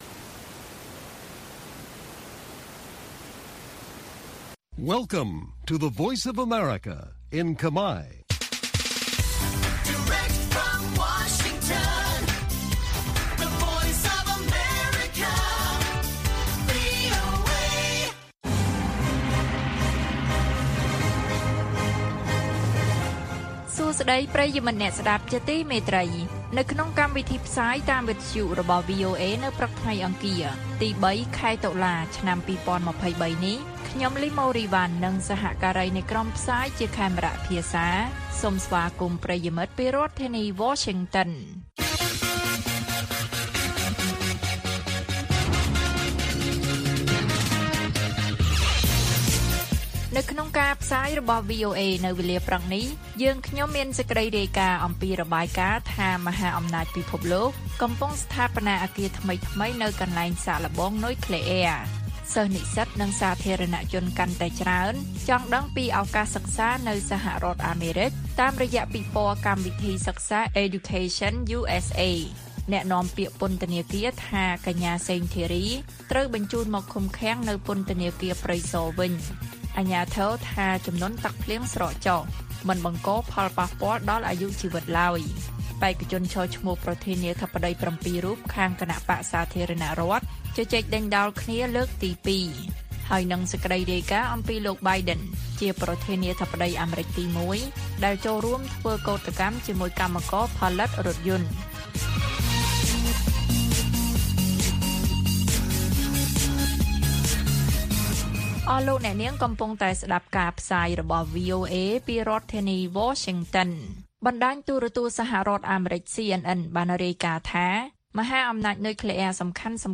ព័ត៌មានពេលព្រឹក ៣ តុលា៖ របាយការណ៍ថា មហាអំណាចពិភពលោកកំពុងស្ថាបនាអគារថ្មីៗនៅកន្លែងសាកល្បងនុយក្លែអ៊ែរ